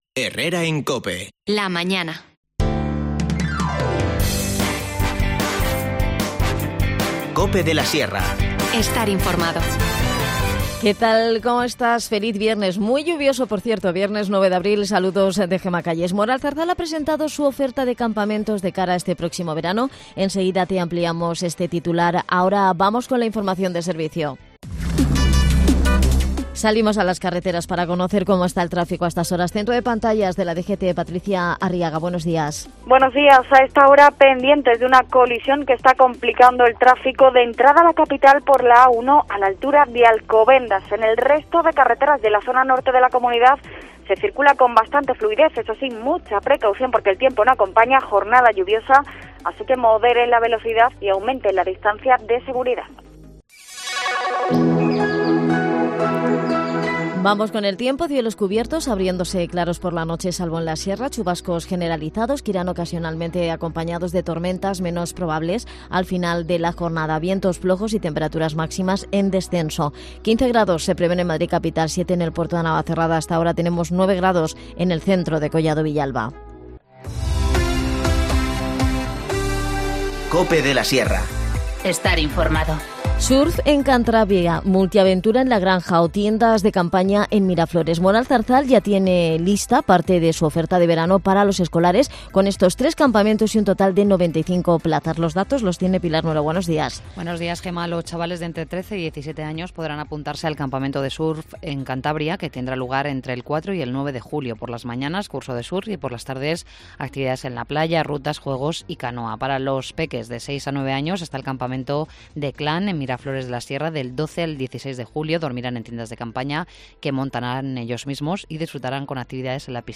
Nos cuenta todos los detalles Jacobo Martín, concejal de Deportes Toda la actualidad en Cope de La Sierra Escucha ya las desconexiones locales de COPE de la Sierra en Herrera en COPE de la Sierra y Mediodía COPE de la Sierra.